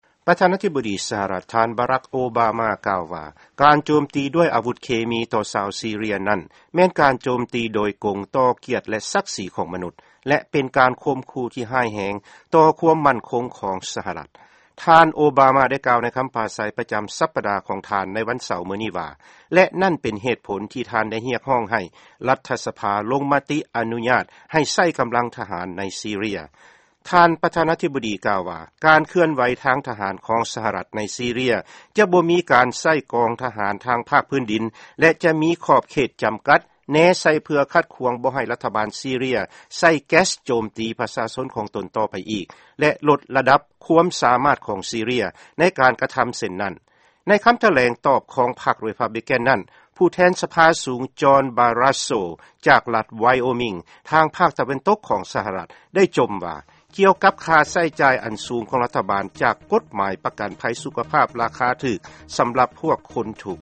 ຟັງຂ່າວ ຄໍາຖະແຫລງຂອງ ປະທານາທິບໍດີ ໂອບາມາ